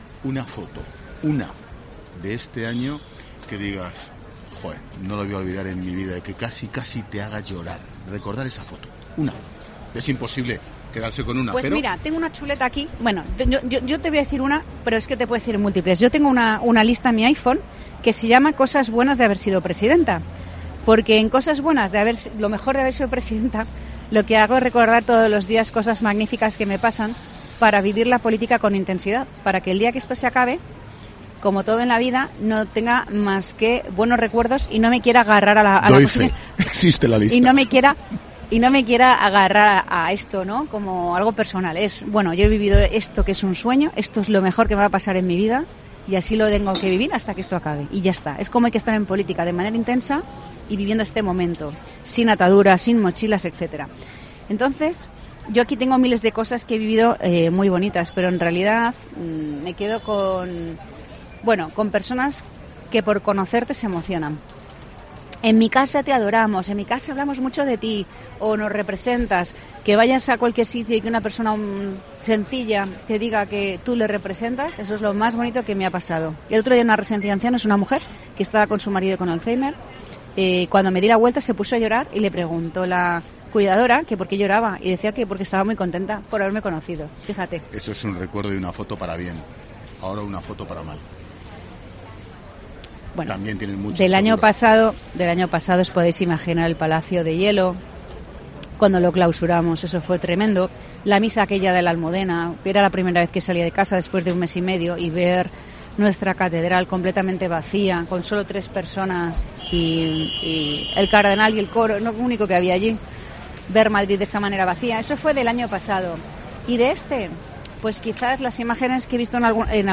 La candidata a la reelección en las elecciones del 4-M en la Comunidad de Madrid ha analizado en 'La Linterna' de COPE los asuntos de actualidad
La presidenta de la Comunidad de Madrid y candidata del PP a la reelección el próximo 4 de mayo, Isabel Díaz Ayuso, ha pasado en las últimas horas por los micrófonos de 'La Linterna' de COPE para ser entrevistada por Ángel Expósito y abordar los diferentes asuntos de interés para los madrileños y españoles.
La presidenta de la Comunidad de Madrid mostraba su emoción al recordar, por ejemplo, los mensajes de cariño que recibe por parte de la ciudadanía: “Me quedo con personas que por conocerte se emocionan: en mi casa te adoramos, hablamos mucho de ti, que vayas a cualquier sitio y que una persona te diga que tú le representas...El otro día en una residencia de ancianos, una mujer que estaba con su marido con Alzheimer.